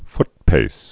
(ftpās)